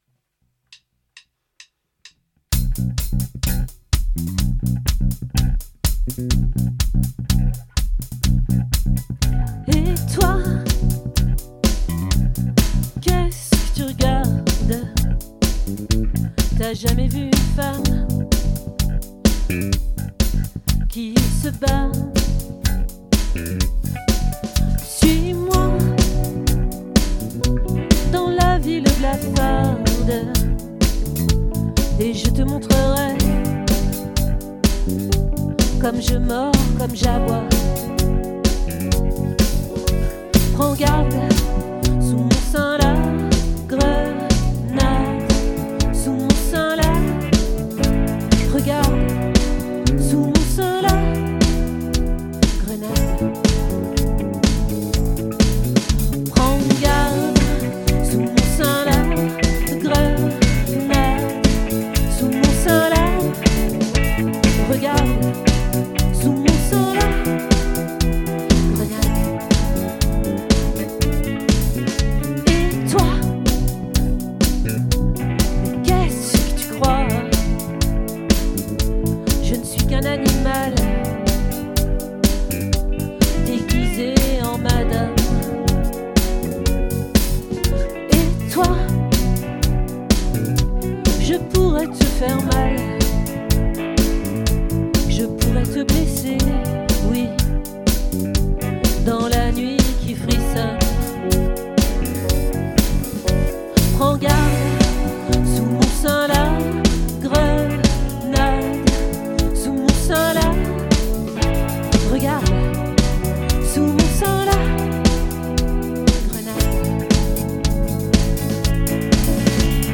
🏠 Accueil Repetitions Records_2022_10_12